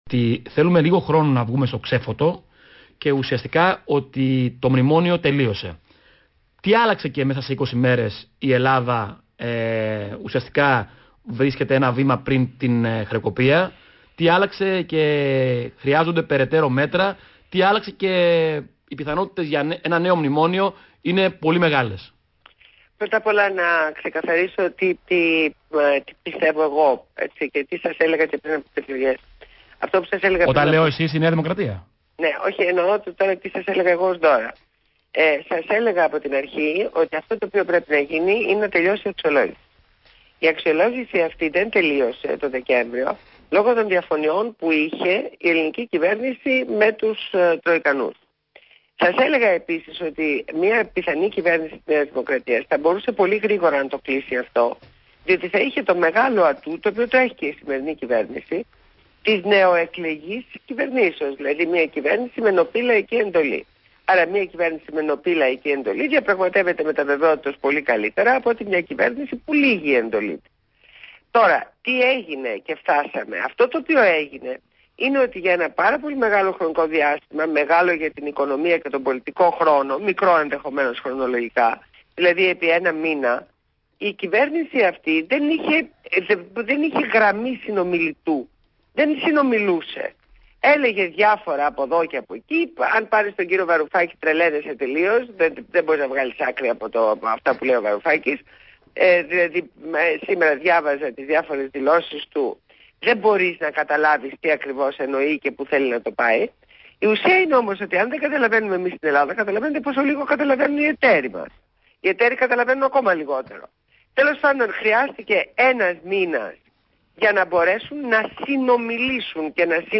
Συνέντευξη στο ραδιόφωνο Παραπολιτικά FM 90,1